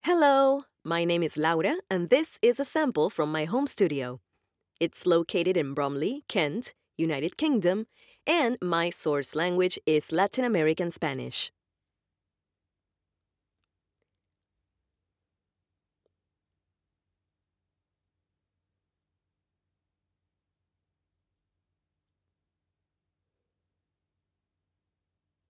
Female
Approachable, Character, Conversational, Corporate, Natural, Warm, Young
Neutral Latin American (native), Argentinian (native), Rioplatense (native), Lightly Accented English
main reel.mp3
Microphone: Manley reference Cardioid